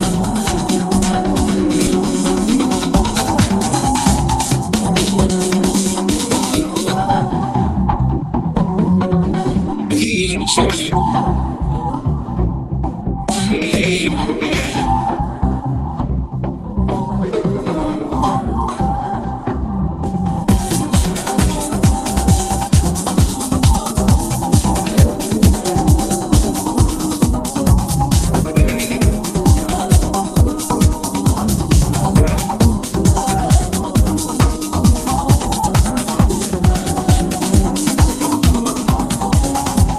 音楽生成AI Musika で自動生成したテクノ曲